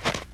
alien-biomes / sound / walking / snow-06.ogg
snow-06.ogg